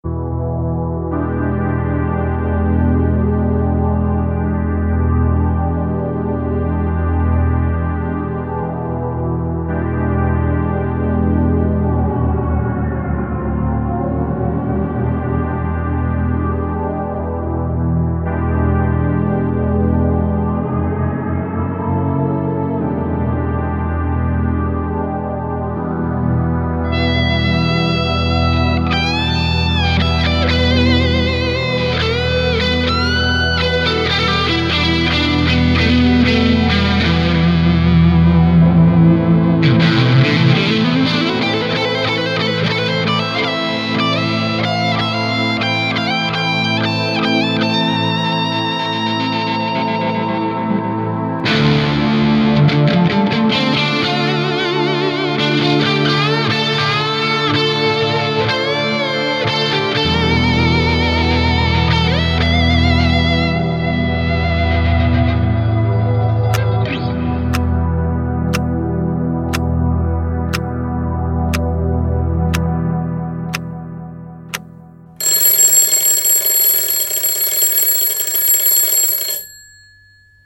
Žánr: Rock
Hard-rock 70-90.let s moderním soundem a aranžemi